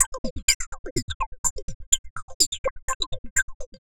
tx_perc_125_harmonichats.wav